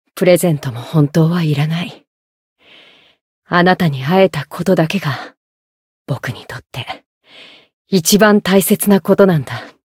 灵魂潮汐-迦瓦娜-七夕（送礼语音）.ogg